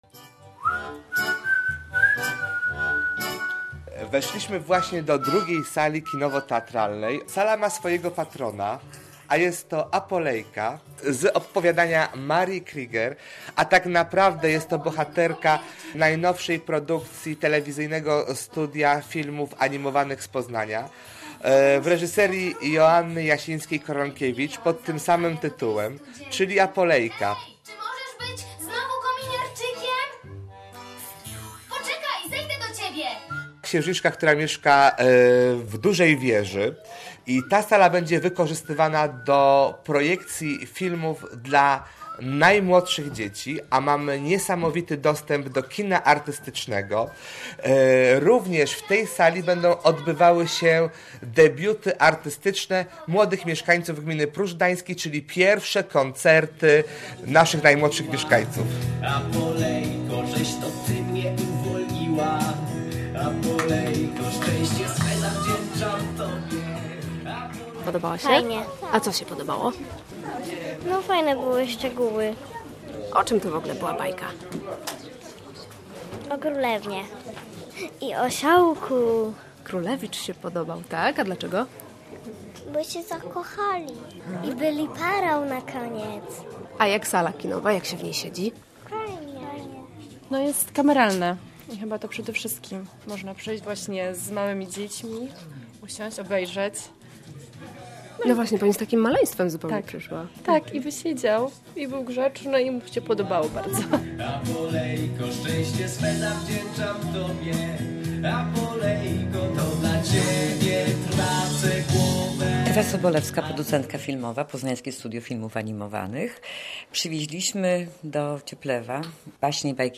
Na uroczystym otwarciu sali kinowej obecne były reżyserki i producentki filmów animowanych dla dzieci ze studia w Poznaniu.